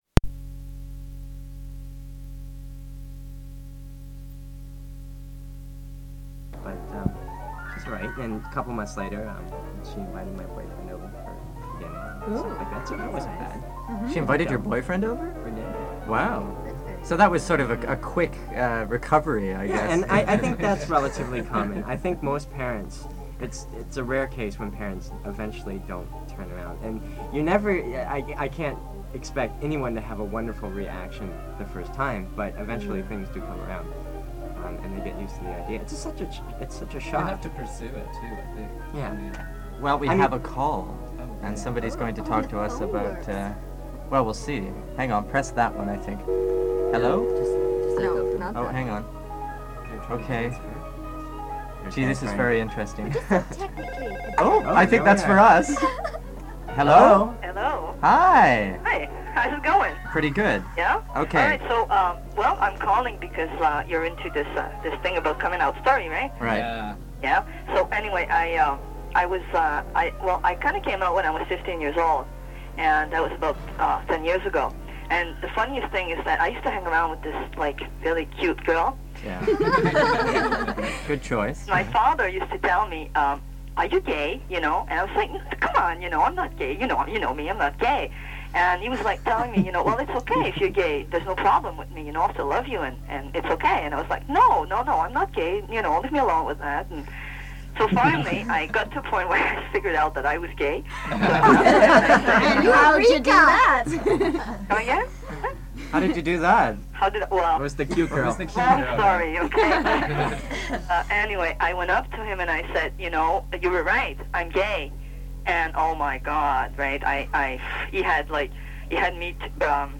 Gay Day was an annual 24 hour broadcast event held by CKUT Radio (which hosted the Dykes on Mykes broadcast), from 1989 to 1991.